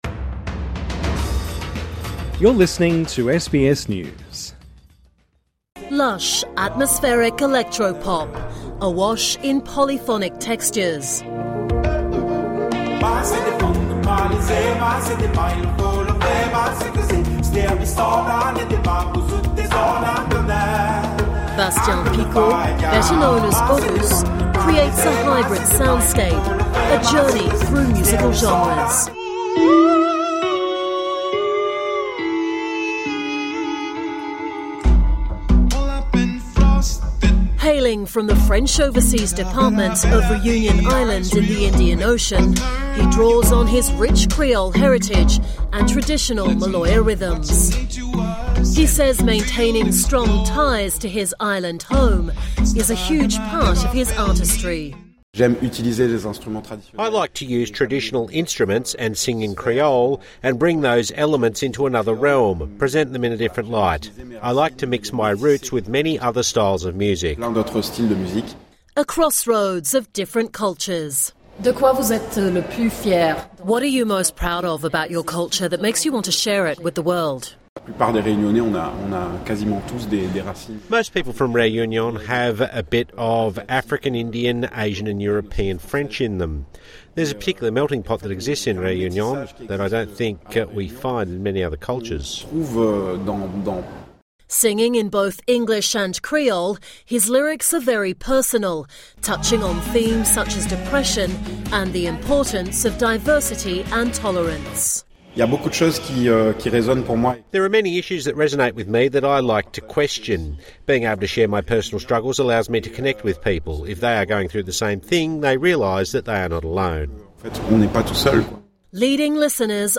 TRANSCRIPT Lush, atmospheric electro-pop, awash in polyphonic textures.